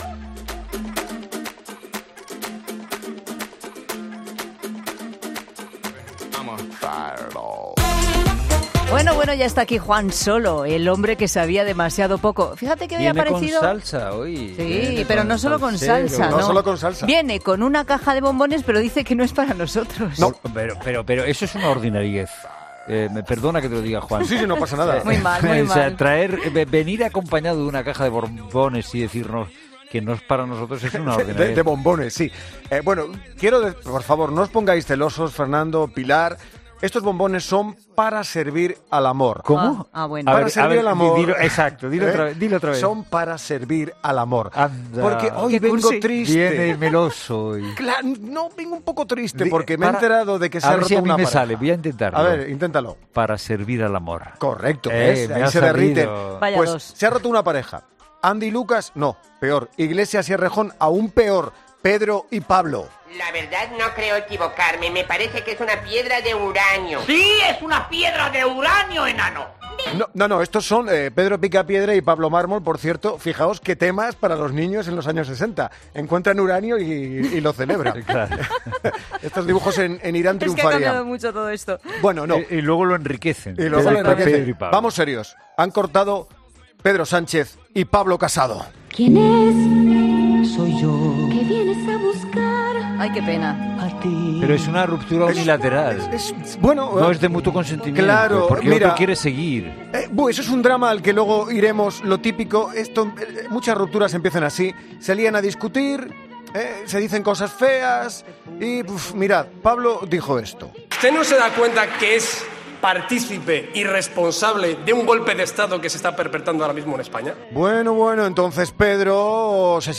Humor: 'El hombre que sabía demasiado poco'